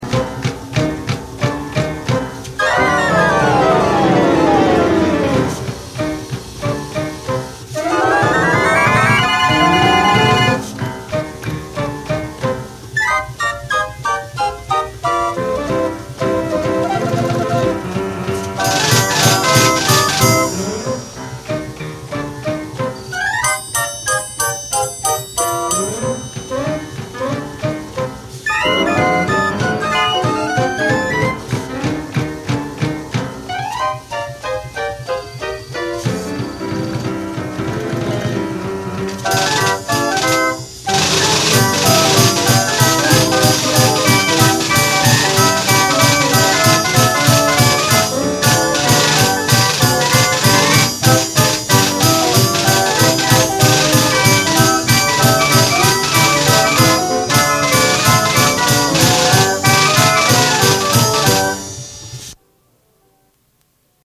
played on a Seeburg G